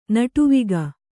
♪ naṭuviga